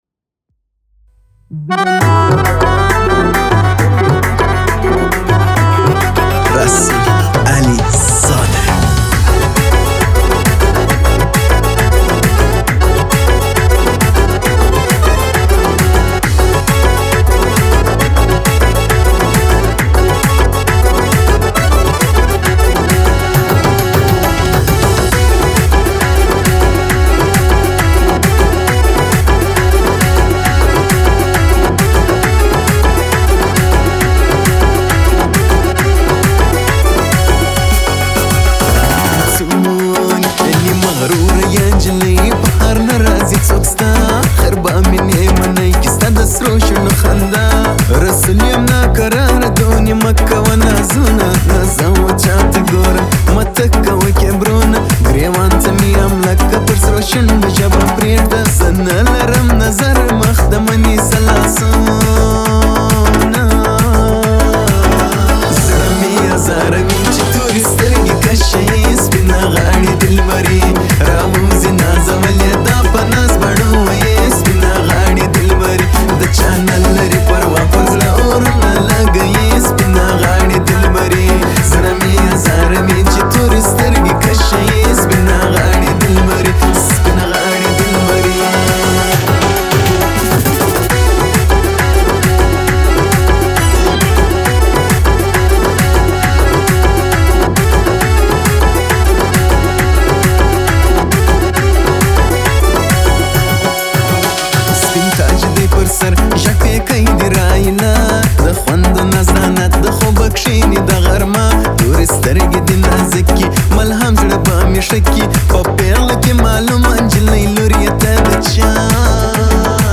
آهنگ افغانی